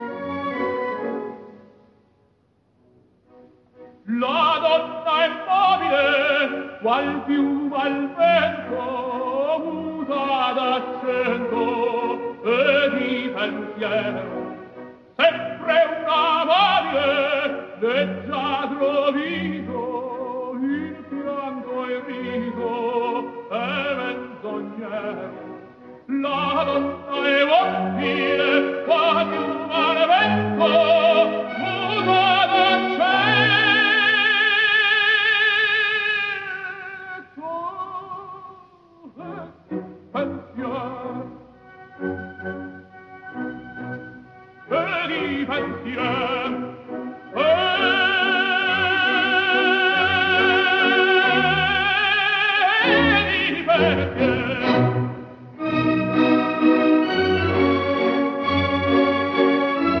Классика